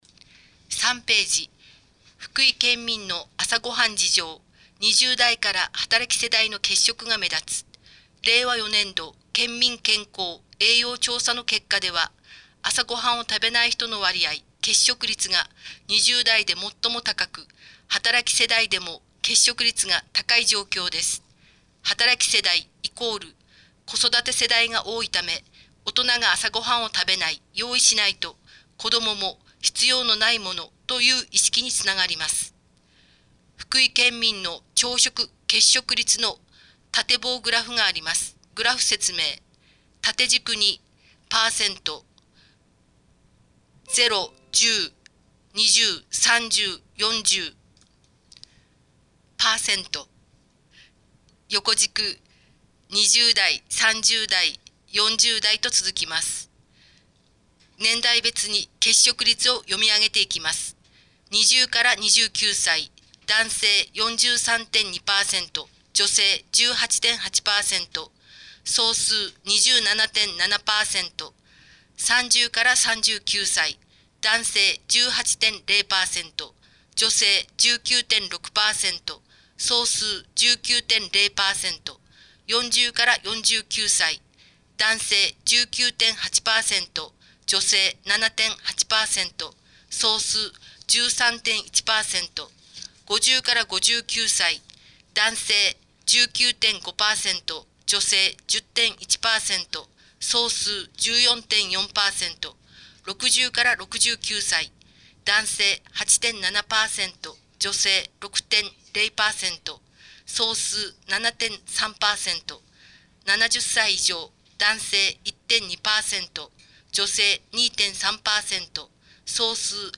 越前市広報６月号（音訳）